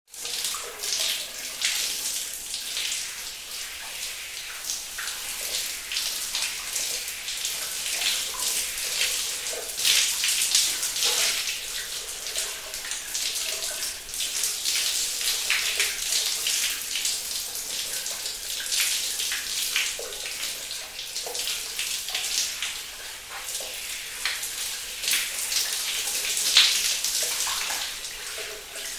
• Качество: 128, Stereo
звук душа